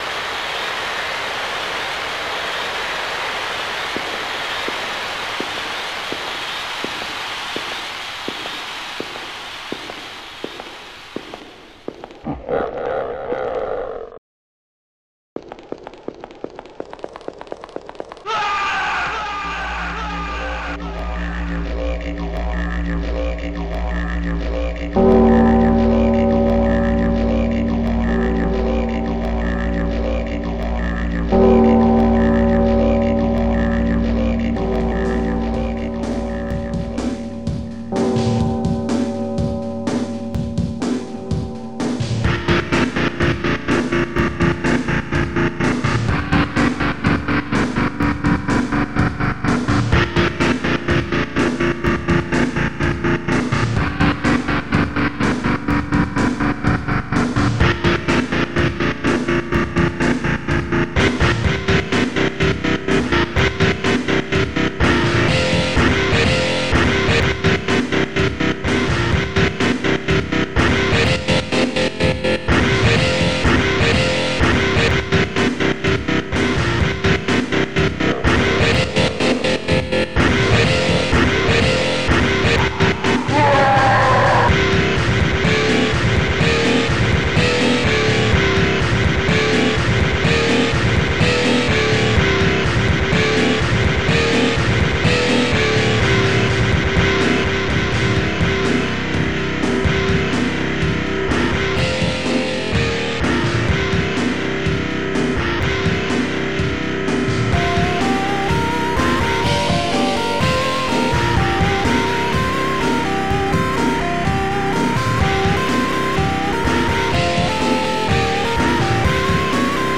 Digital Symphony Module  |  1995-04-23  |  196KB  |  2 channels  |  44,100 sample rate  |  3 minutes, 19 seconds